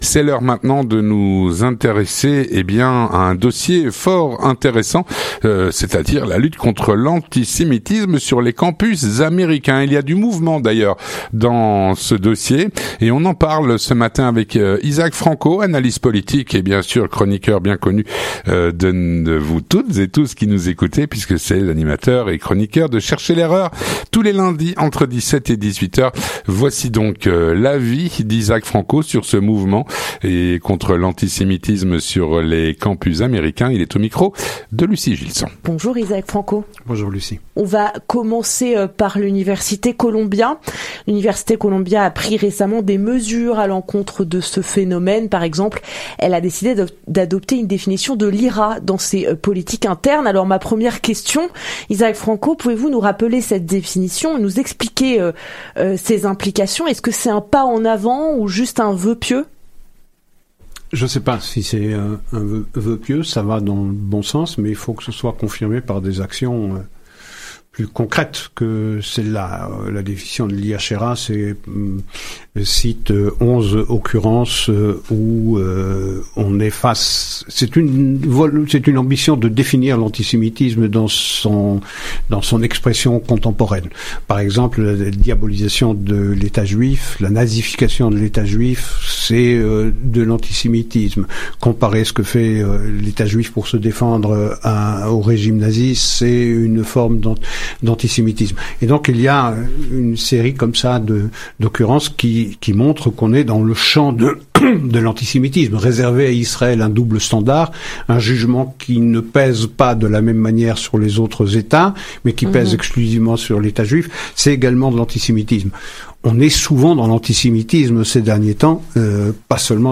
L'entretien du 18H - La lutte contre l’antisémitisme sur les campus américains.